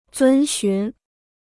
遵循 (zūn xún) 무료 중국어 사전